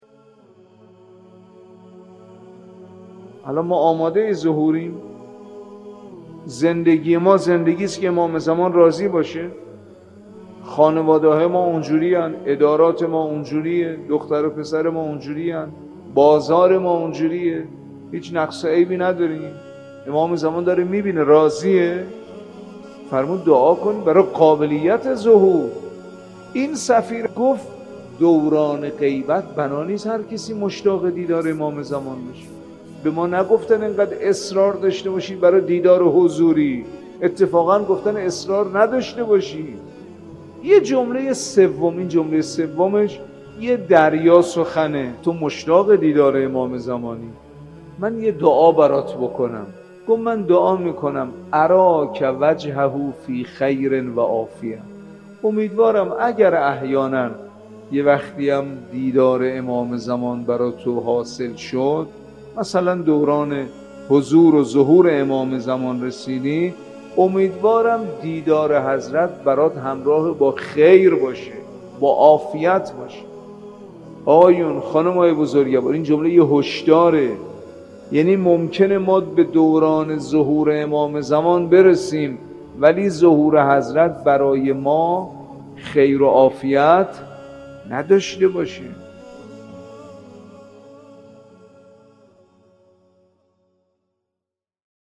مجموعه پادکست «جلوه‌ای از بندگی» با کلام اساتید به نام اخلاق به کوشش ایکنا گردآوری و تهیه شده است